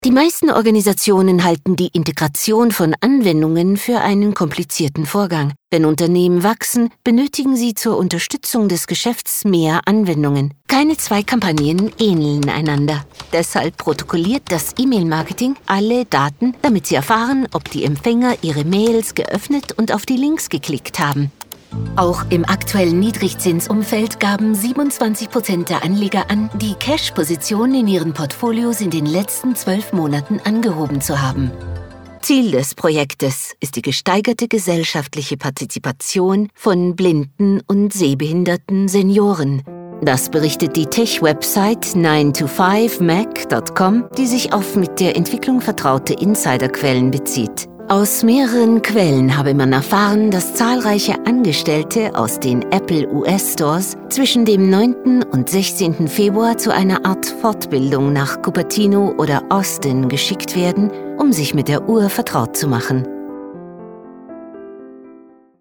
Native speaker Female 30-50 lat
Smooth, classy and precise mid-range voice.
Nagranie lektorskie